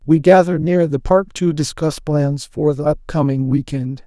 TTS_audio / PromptTTS++ /sample2 /Template2 /Condition /Friends /Adv /loudness /quiet.wav
quiet.wav